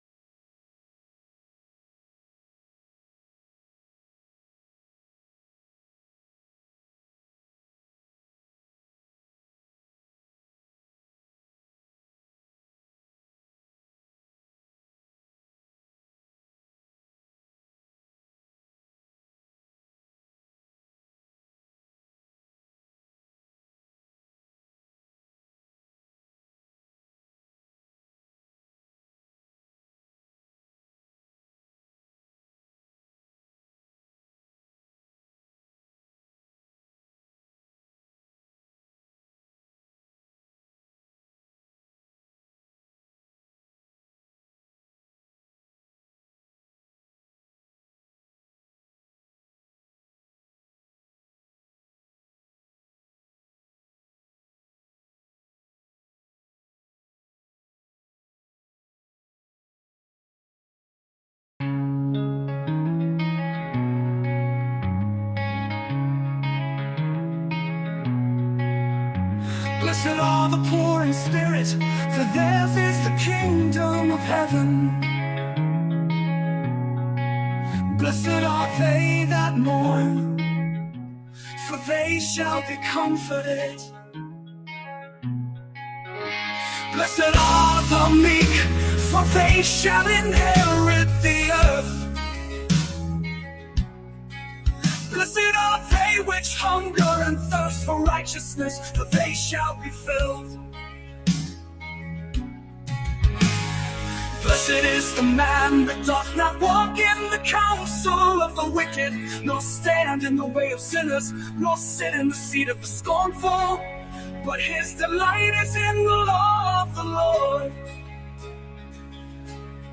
Talk Show Episode, Audio Podcast, Sons of Liberty Radio and When Men Exalt Themselves The LORD Will Humble Them on , show guests , about When Men Exalt Themselves The LORD Will Humble Them, categorized as Education,History,Military,News,Politics & Government,Religion,Christianity,Society and Culture,Theory & Conspiracy